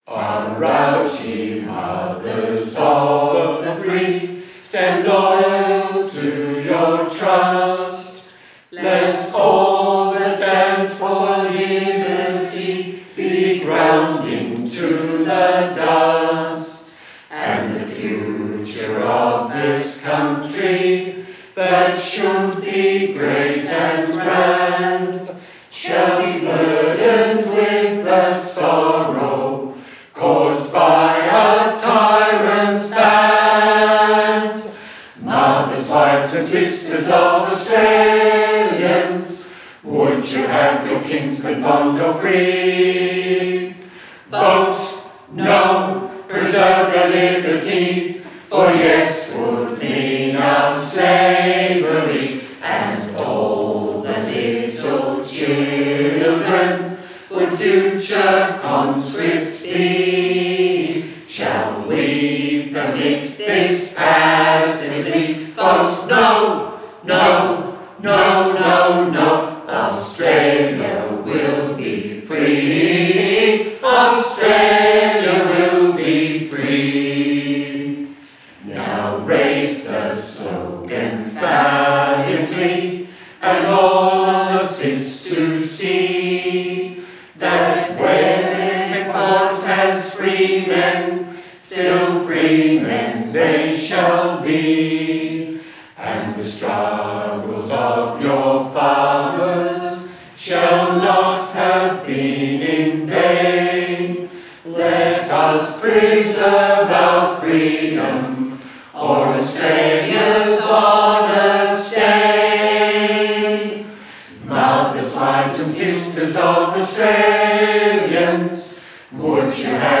The recordings - The Victorian Trade Union Choir
The Victorian Trade Union Choir very kindly recorded some of these songs for the exhibition A Nation Divided: The Great War and Conscription.